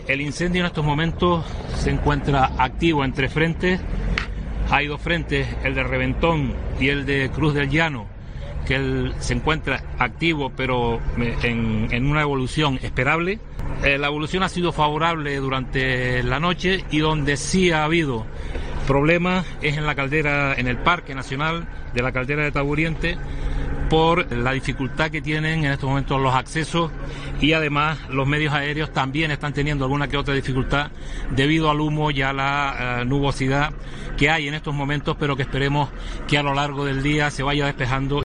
Manuel Miranda, consejero de Política Territorial, informa de la evolución del incendio de La Palma